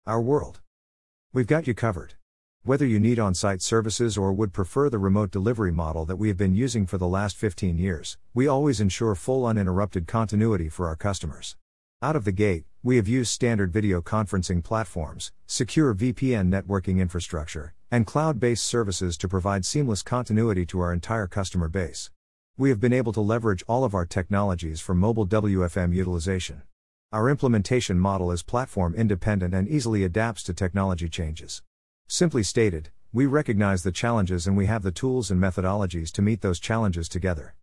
easytts_audio_Our-World1-6.mp3